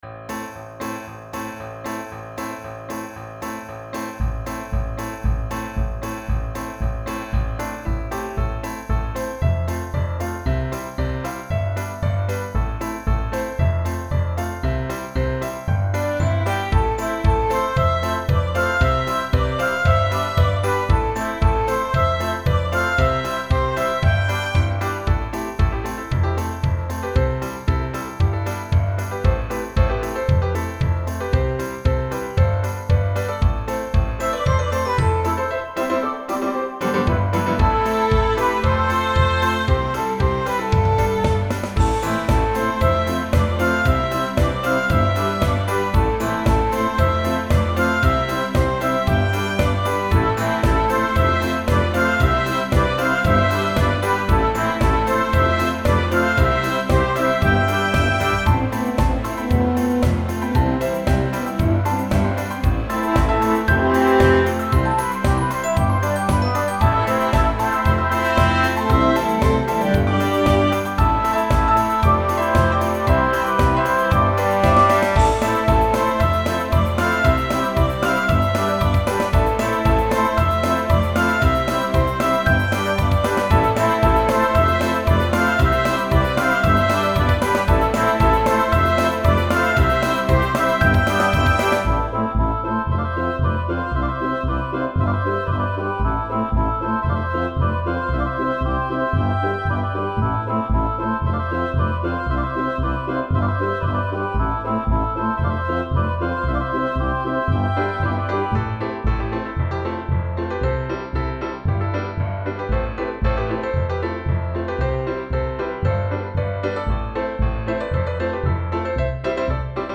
music piano orchestra instrumental